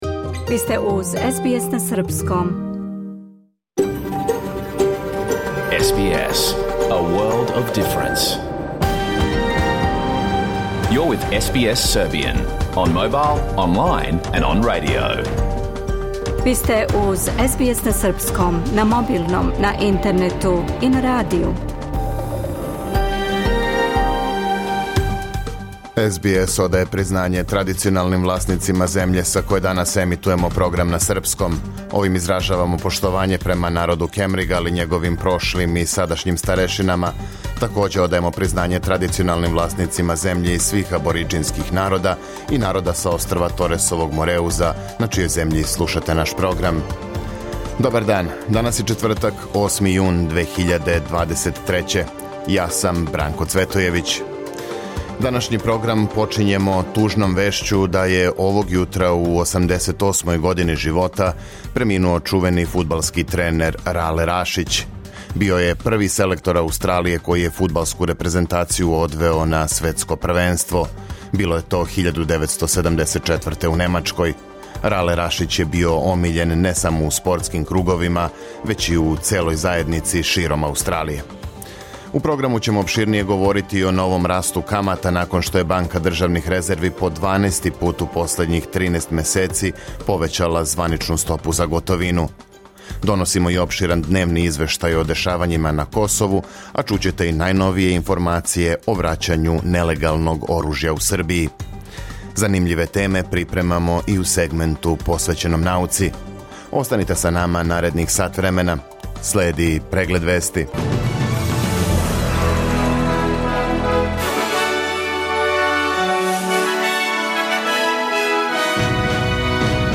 Програм емитован уживо 8. јуна 2023. године
Уколико сте пропустили данашњу емисију, можете је послушати у целини као подкаст, без реклама.